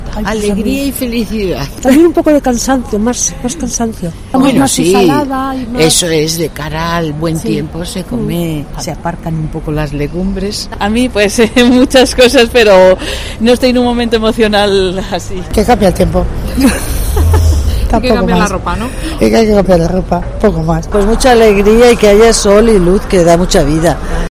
Los salmantinos opinan sobre la llegada de la primavera